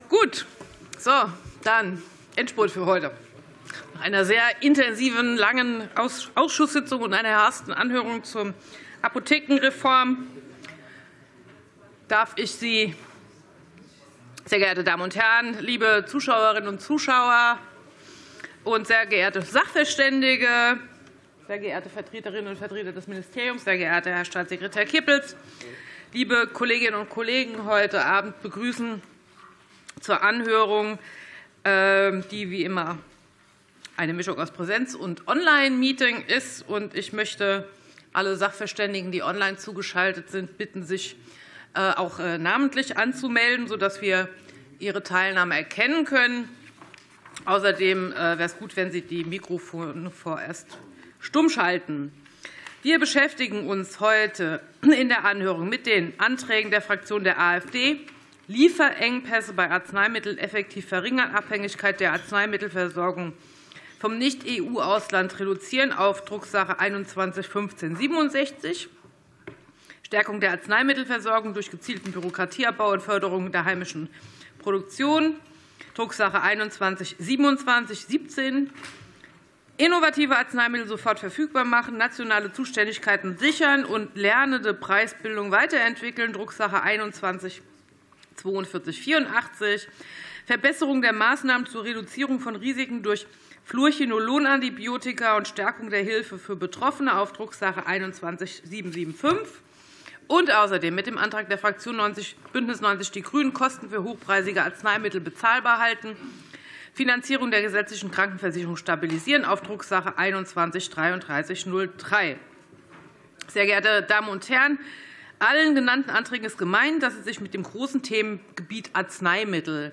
Anhörung zu Anträgen zur Arzneimittelversorgung